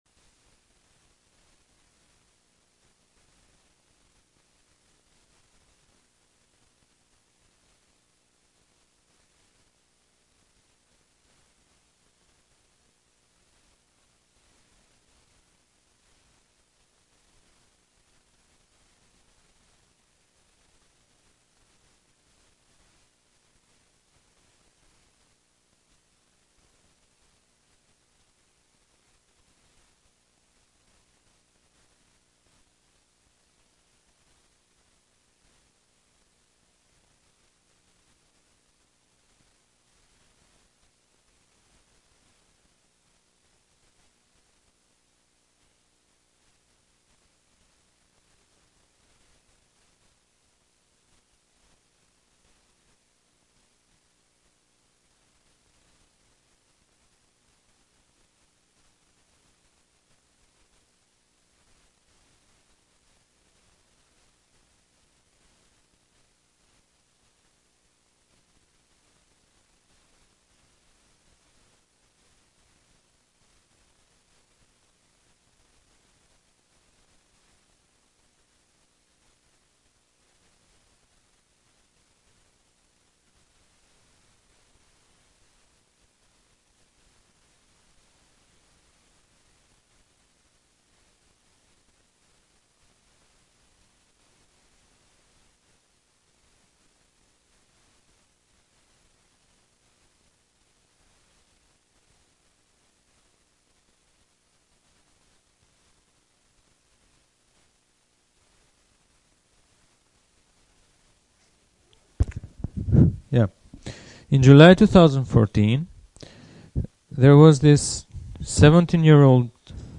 Lecture 3